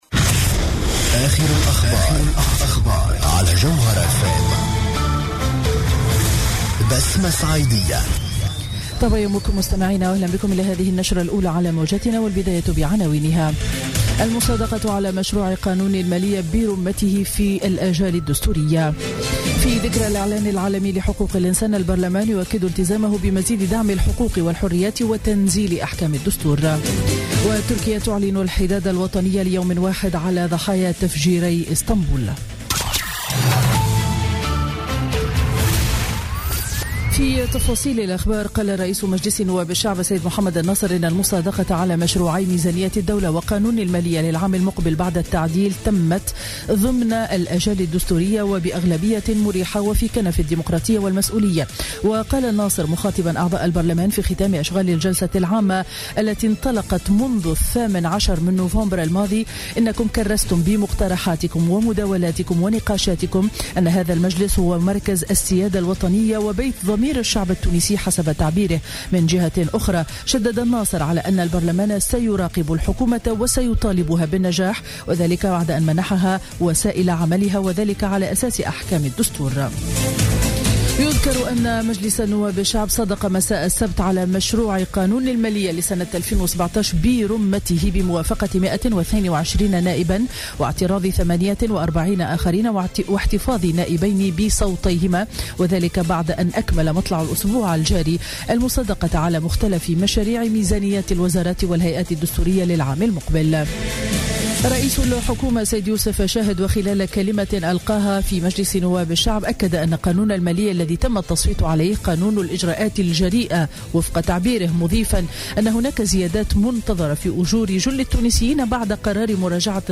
نشرة أخبار السابعة صباحا ليوم الأحد 11 ديسمبر 2016